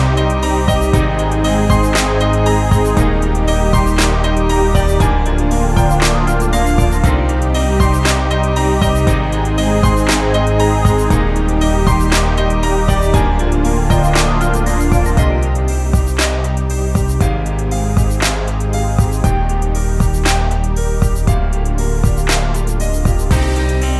With Rapper R'n'B / Hip Hop 4:10 Buy £1.50